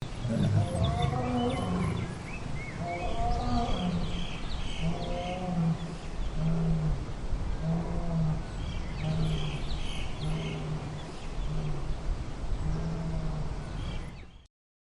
Lioness Mating Call
Lioness Mating Call is a free sfx sound effect available for download in MP3 format.
yt_t_81pvf6zTQ_lioness_mating_call.mp3